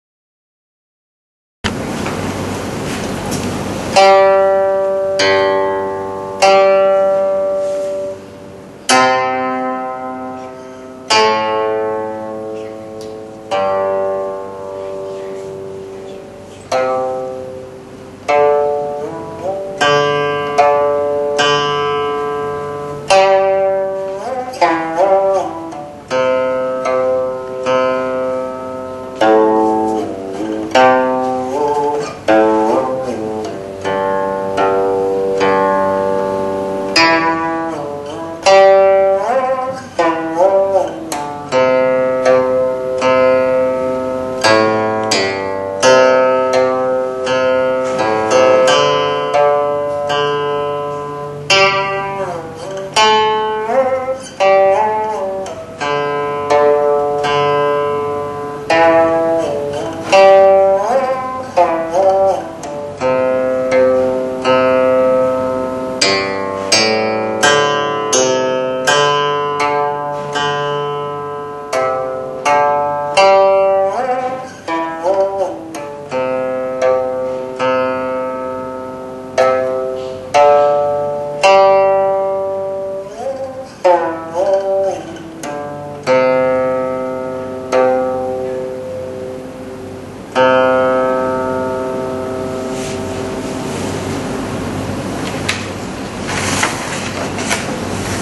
古琴教学示范